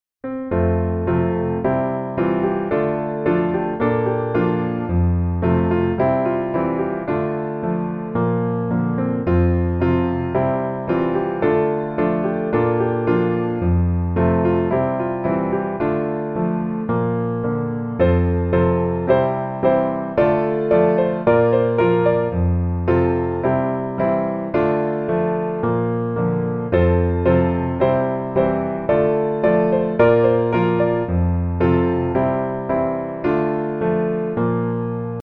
F Majeur